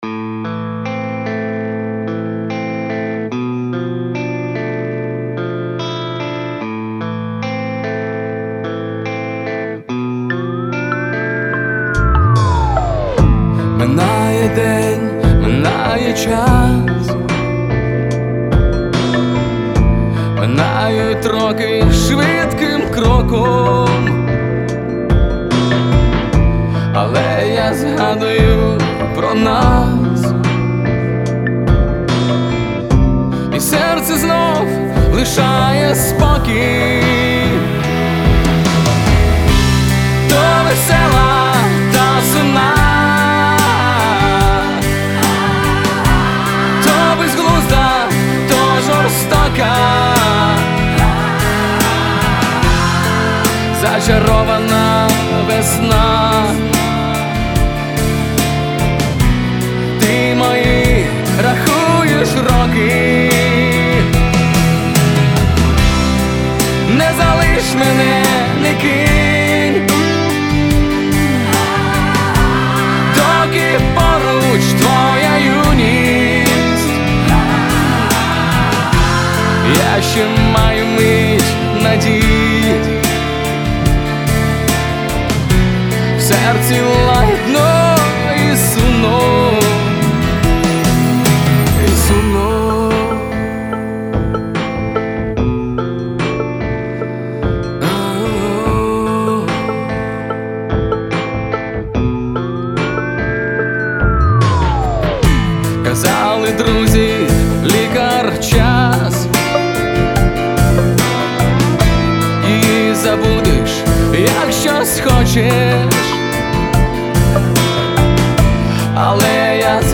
Версия 3 — более открыта.